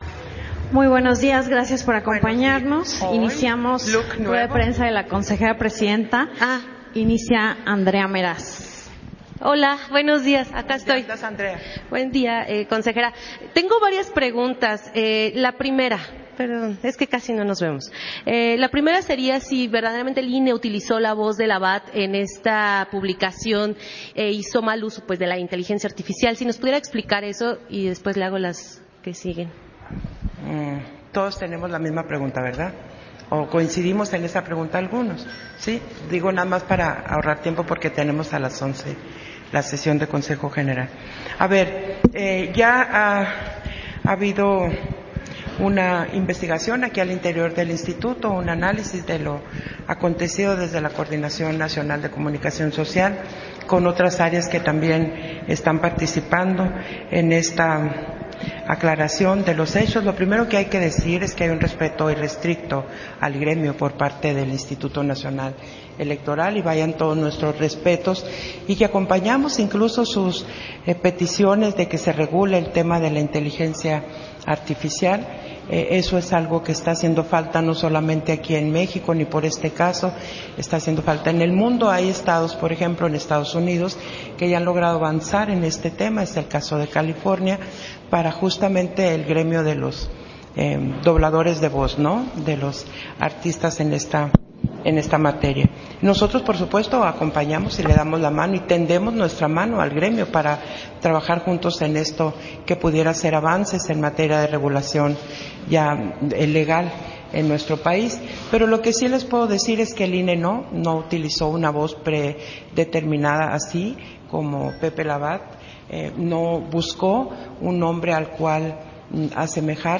Conferencia de Prensa ofrecida por la Consejera Presidenta, Guadalupe Taddei, 15 de julio de 2025
Versión estenográfica de la conferencia de prensa ofrecida por la Consejera Presidenta del INE, Guadalupe Taddei, a diversos medios de comunicación